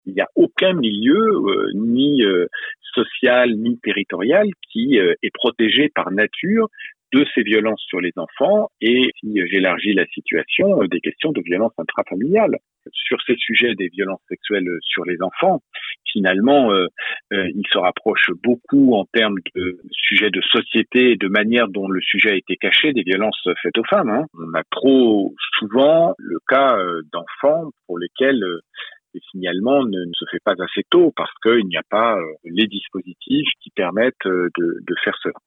Daniel Goldberg, président de l'Uniopss, était à notre antenne. Il alerte sur le manque de structures d’accueil et de personnel.